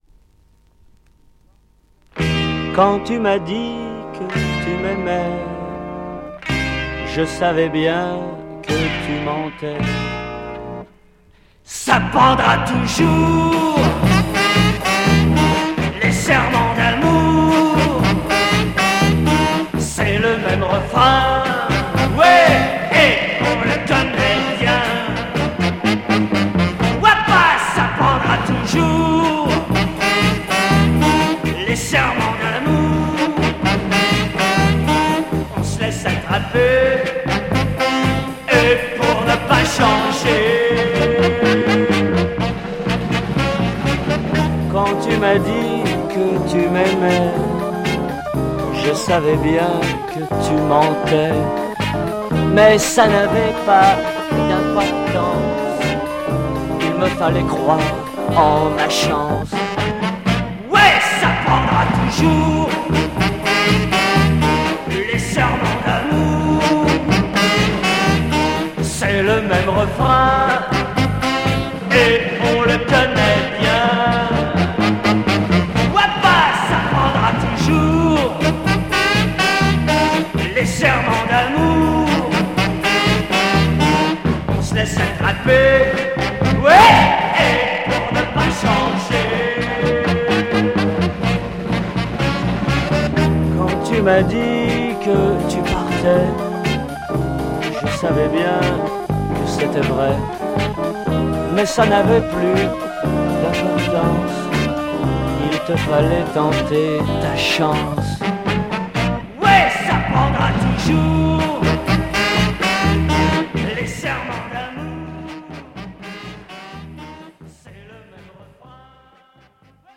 Obscure French Private R&B Mod Garage punk lo-fi EP
quite lo-fi recording